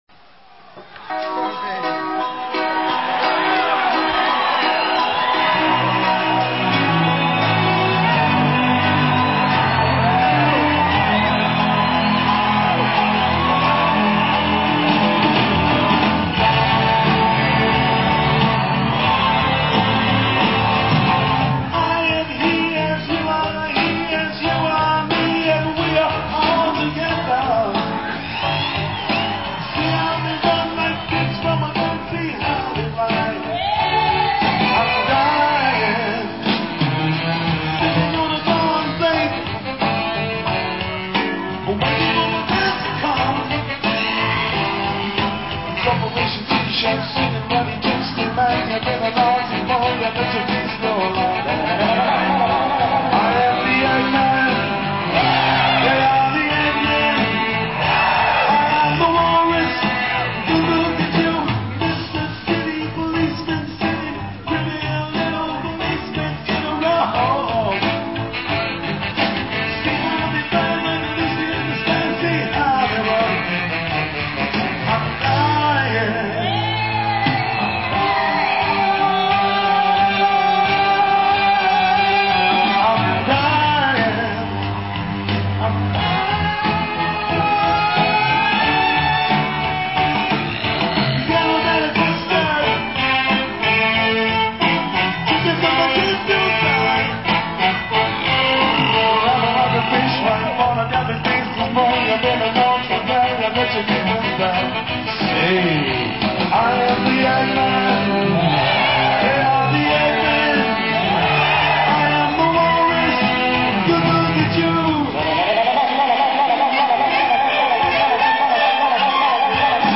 Lead vocals